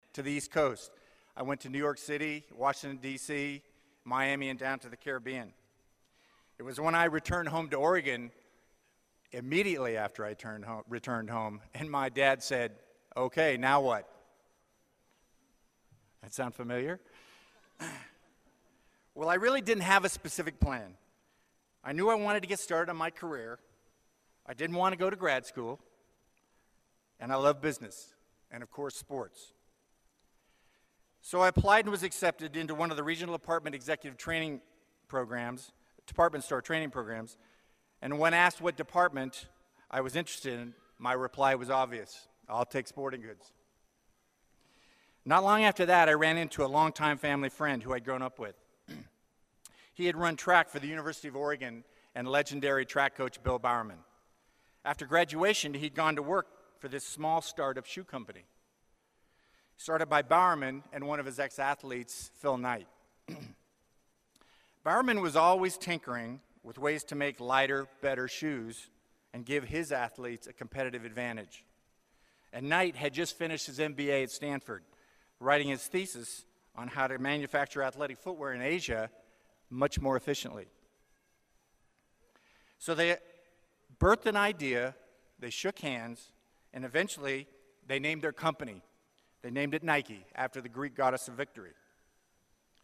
公众人物毕业演讲第372期:查理邓森2013在犹他州立大学(3) 听力文件下载—在线英语听力室